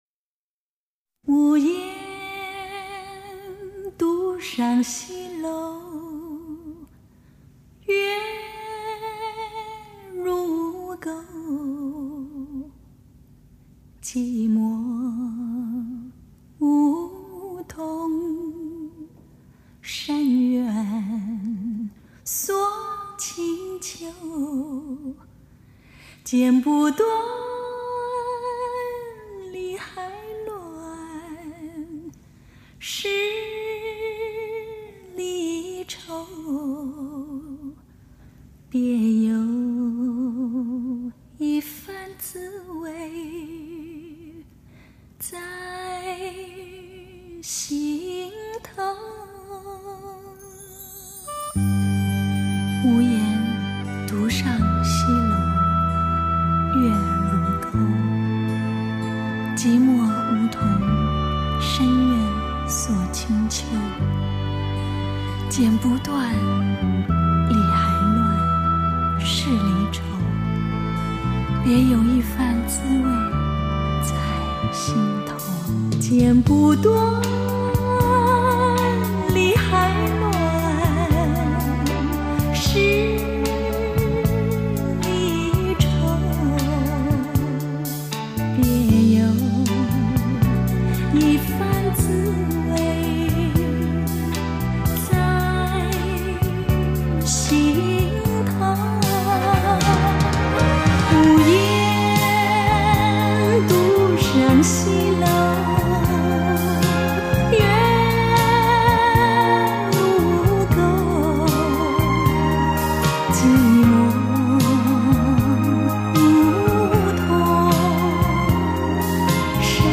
HI-FI顶级人声测试天碟